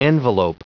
Prononciation du mot envelope en anglais (fichier audio)
Prononciation du mot : envelope